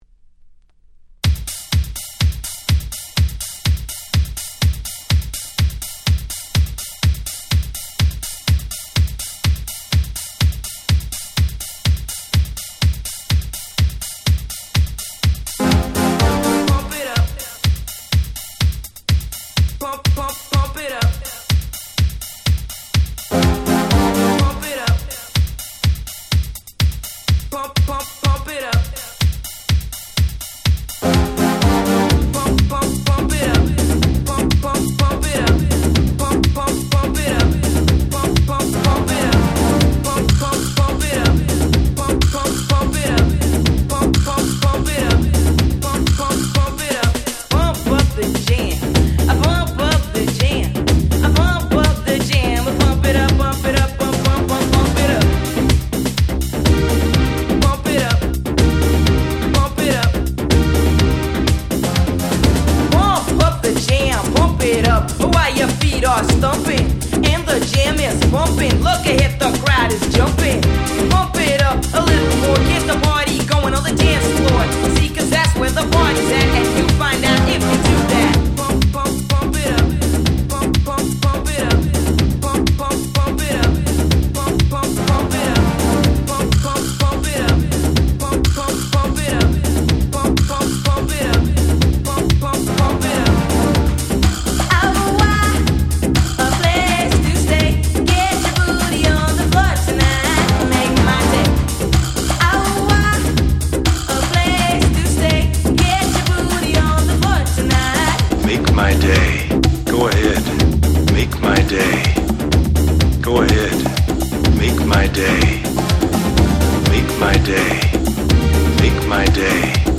【Media】Vinyl 12'' Single
オリジナルをさらにフロア仕様に！！